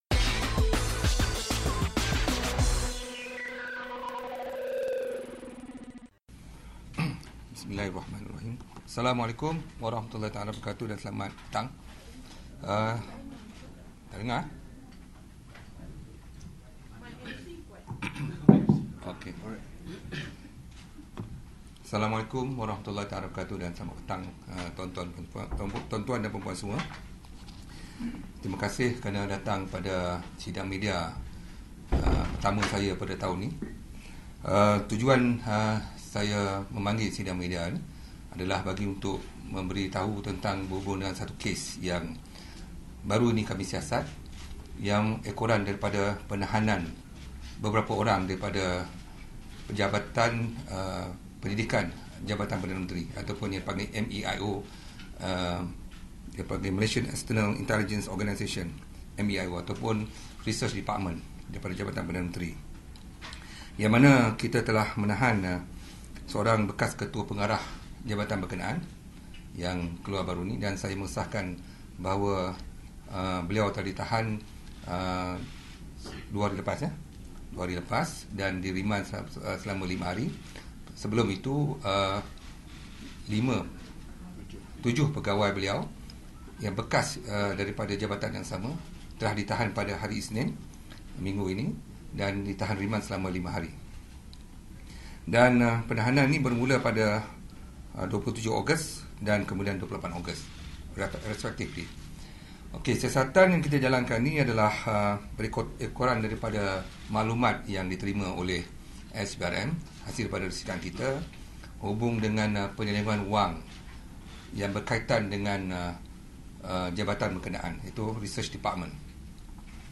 Sidang Media Khas SPRM